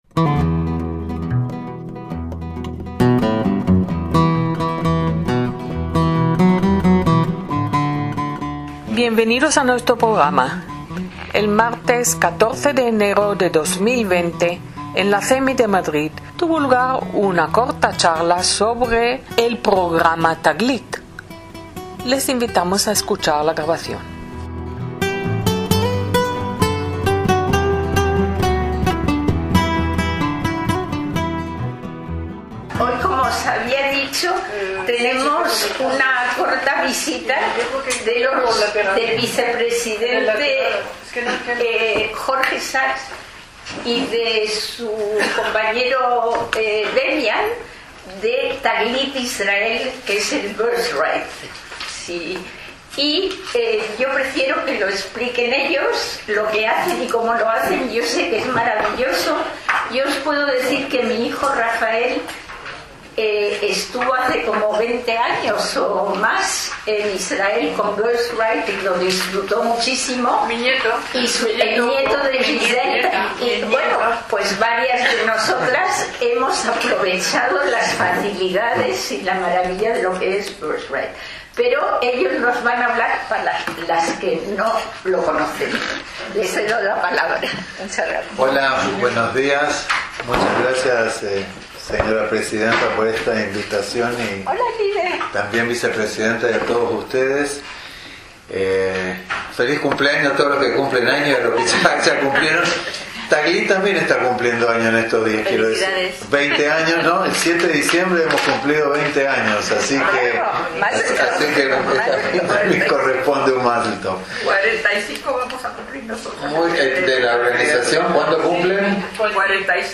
Presentación de Taglit en CEMI